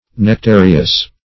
Search Result for " nectareous" : The Collaborative International Dictionary of English v.0.48: Nectareous \Nec*ta"re"ous\, a. Of, pertaining to, containing, or resembling nectar; delicious; nectarean.